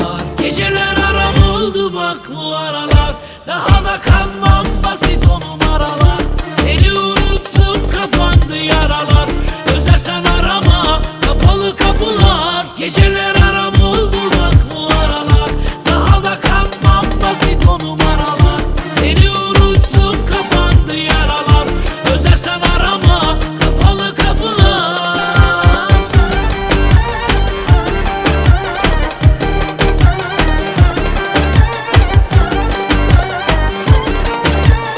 Жанр: Турецкая поп-музыка